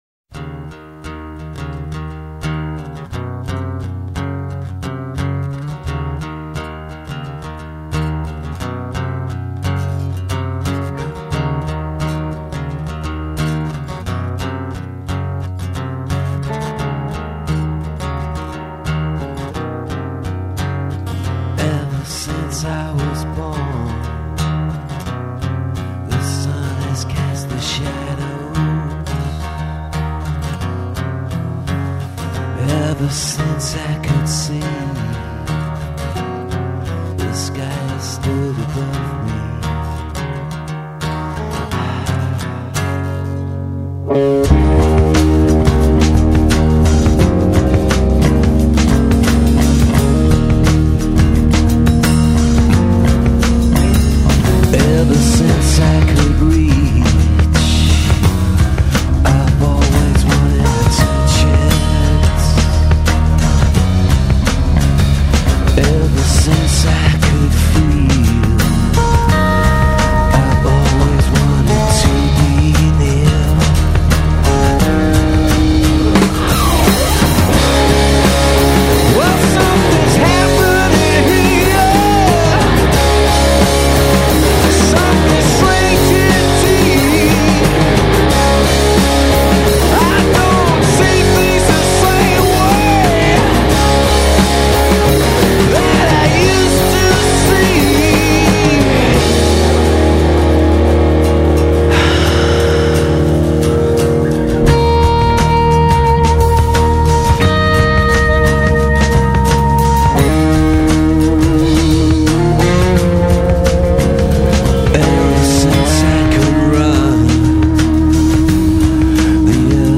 Demonstration recording, recorded in Wales 1998/1999